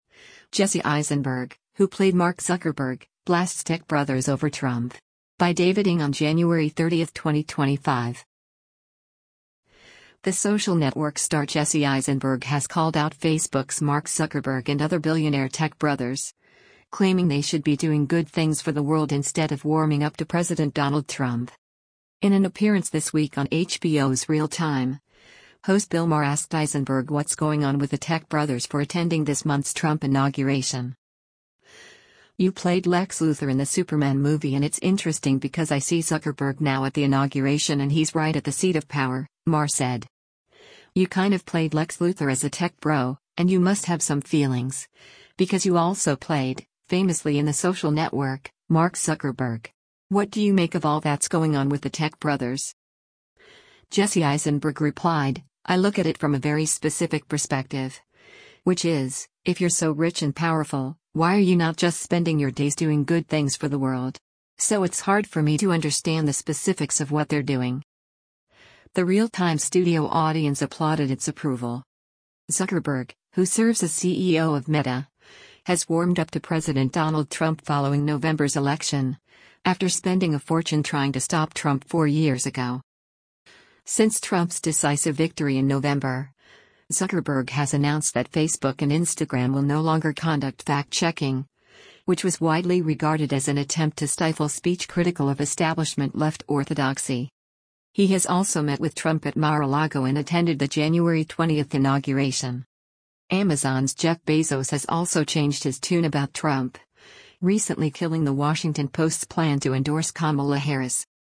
In an appearance this week on HBO’s Real Time, host Bill Maher asked Eisenberg “what’s going on with the tech bros”   for attending this month’s Trump inauguration.
The Real Time studio audience applauded its approval.